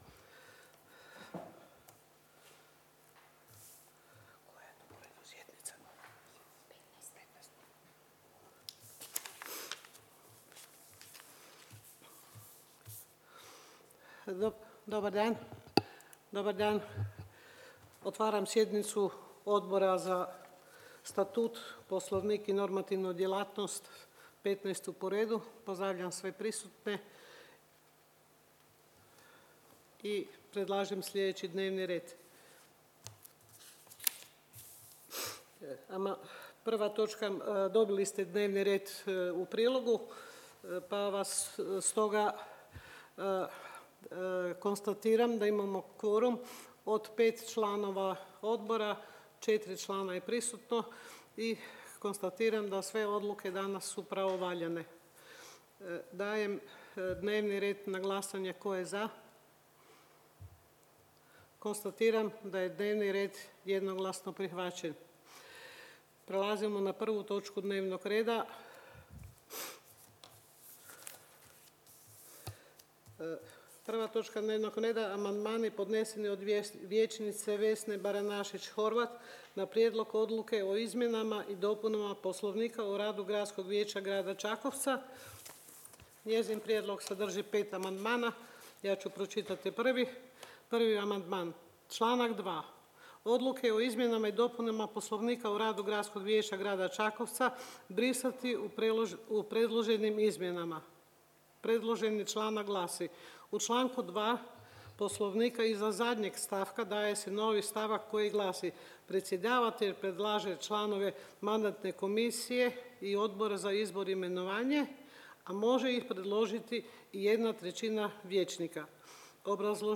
Obavještavam Vas da će se 15. sjednica Odbora za Statut, Poslovnik i normativnu djelatnost Gradskog vijeća Grada Čakovca održati dana 23. studenog 2023. (četvrtak) u 10.30 sati, u vijećnici Uprave Grada Čakovca.